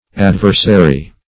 Adversary \Ad"ver*sa*ry\, a.